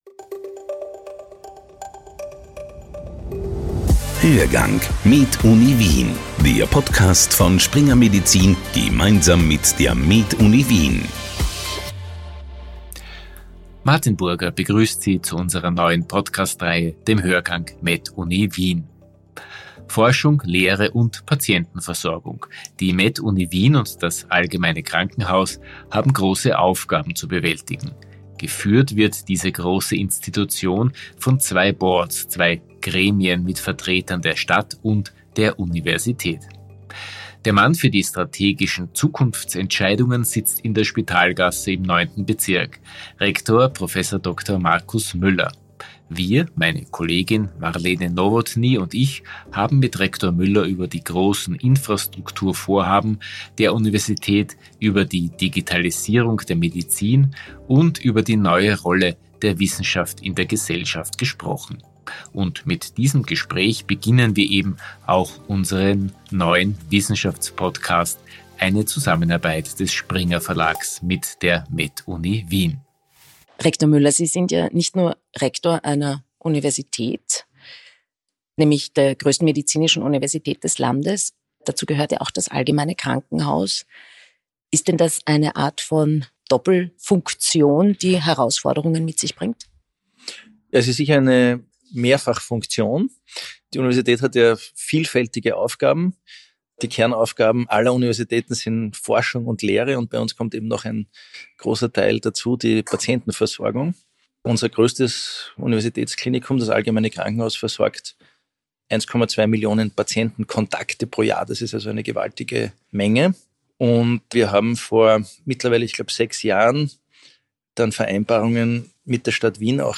MedUni Wien-Rektor Markus Müller im Gespräch